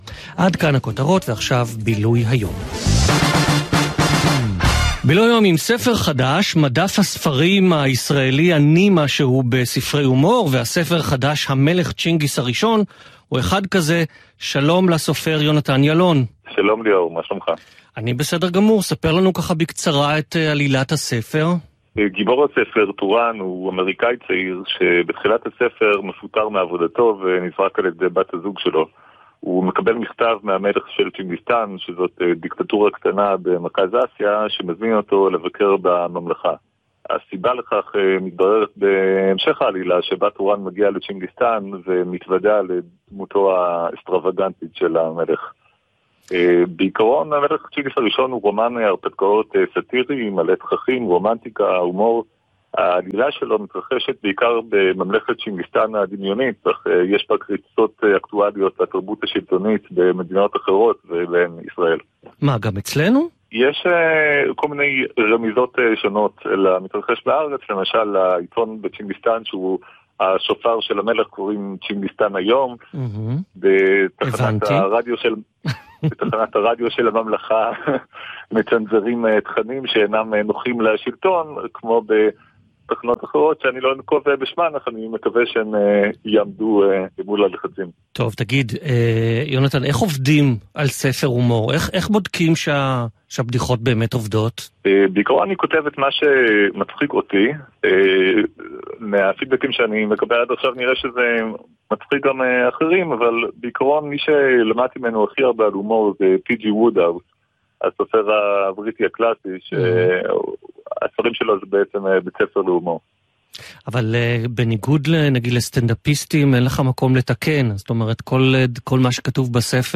ראיון בגלי צה"ל
בניגוד לראיון ברשת א', זה דווקא היה טלפוני.